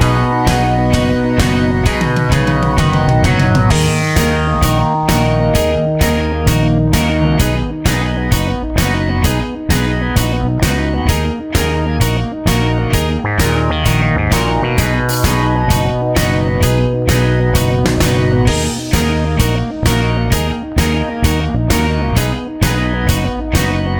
no Backing Vocals Soft Rock 3:17 Buy £1.50